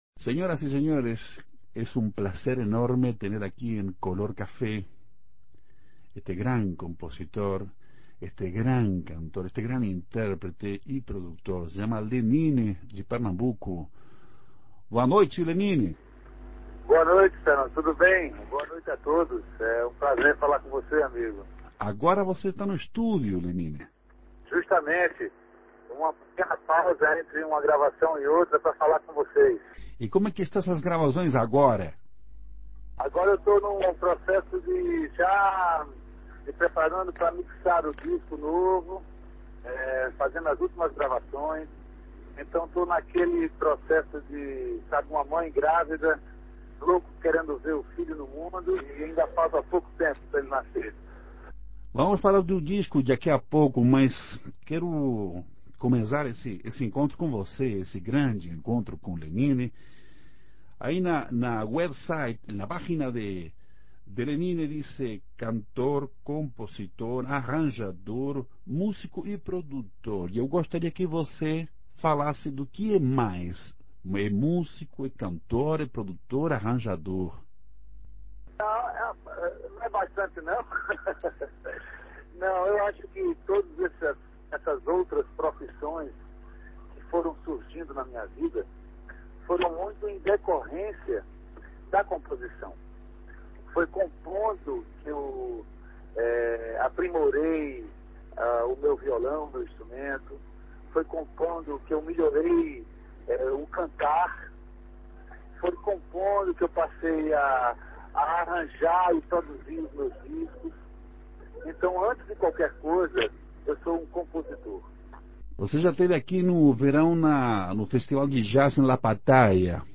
Entrevista exclusiva con Lenine